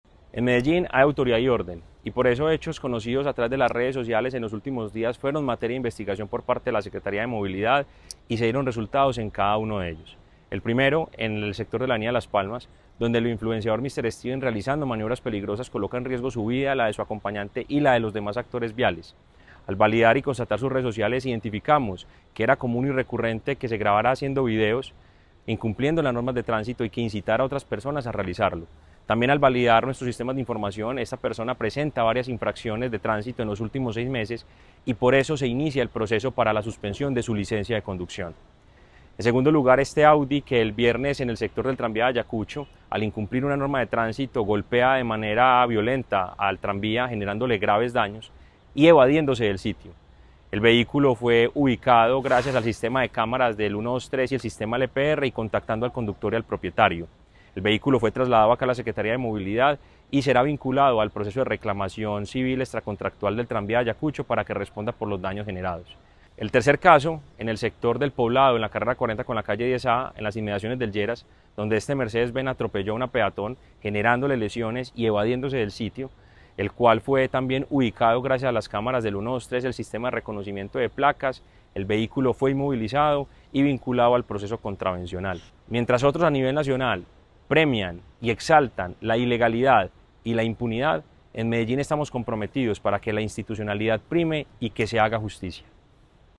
Declaraciones-secretario-de-Movilidad-Mateo-Gonzalez-Benitez.mp3